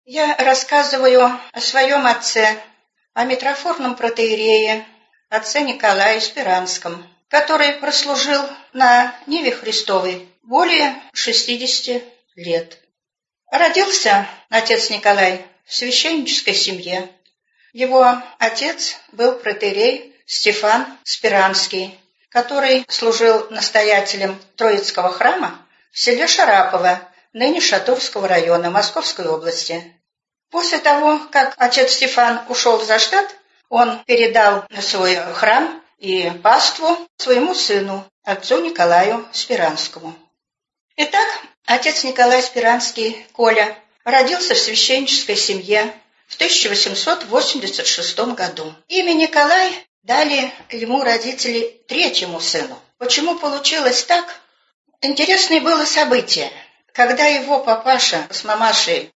Аудиокнига Священноисповедник Николай Сперанский.